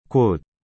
A pronúncia correta, com o som aspirado no final, traz um charme único ao termo.